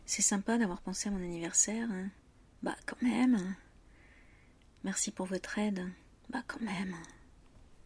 Une sorte de protestation :